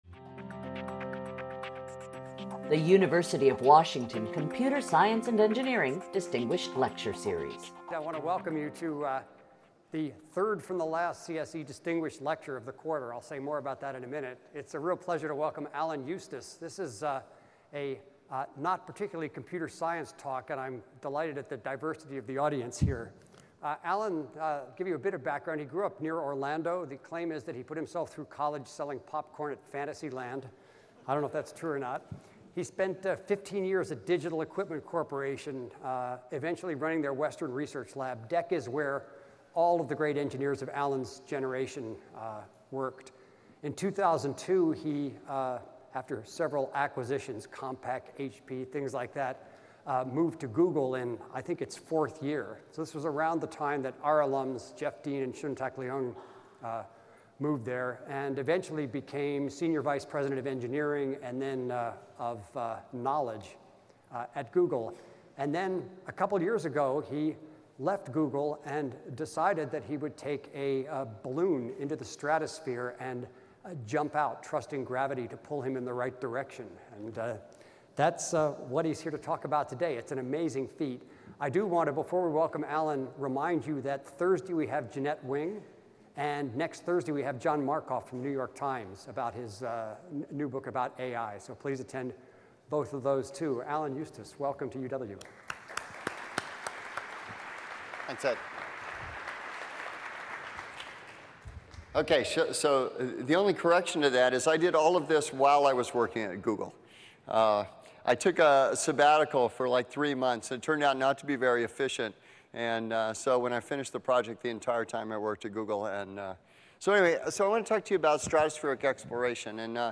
CSE Distinguished Lecture Series